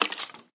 sounds / mob / skeleton / step4.ogg
step4.ogg